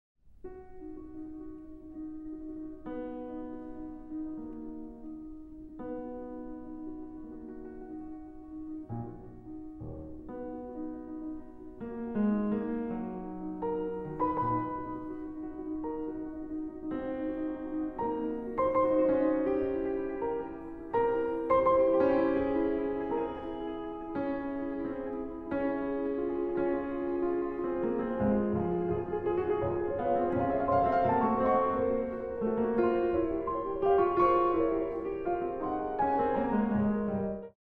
Arrangement for 2 pianos, 8 hands